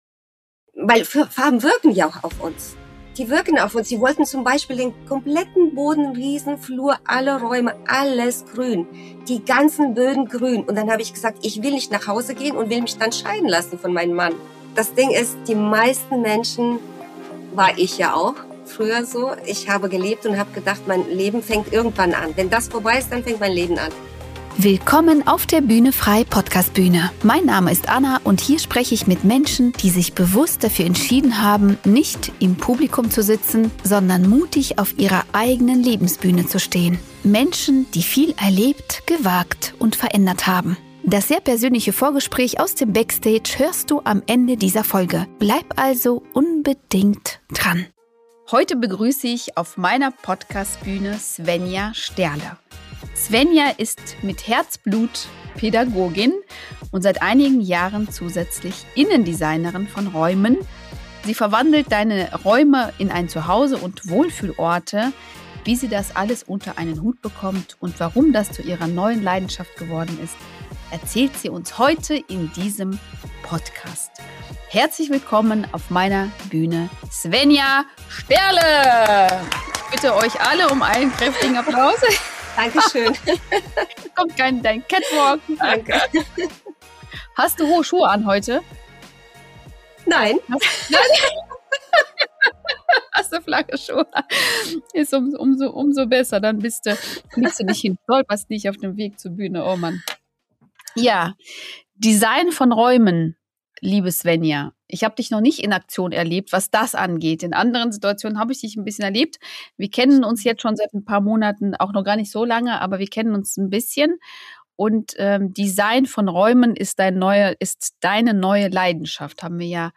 sehr berührendes und inspirierendes Gespräch